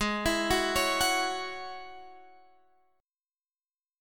Listen to G#7sus4#5 strummed